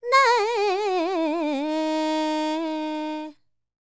QAWALLI 15.wav